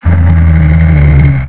boil_idle2.wav